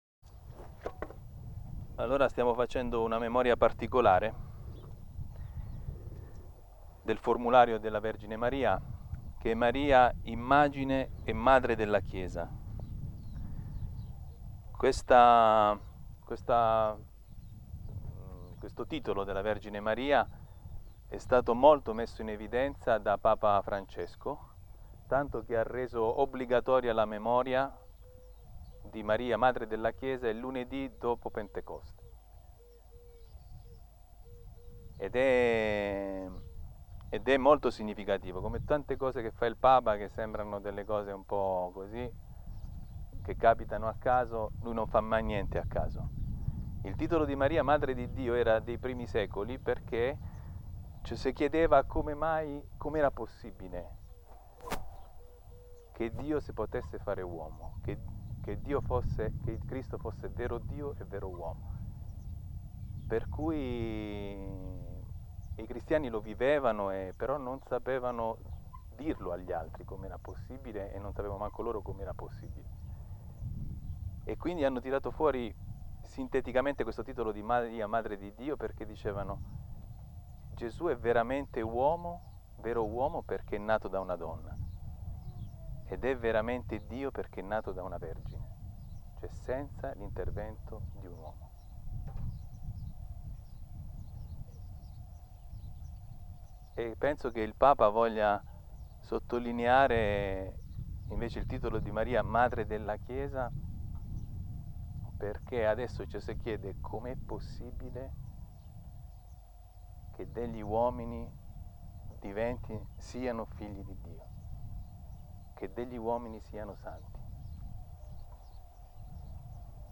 12 Giugno 2020, Maria Madre della Chiesa.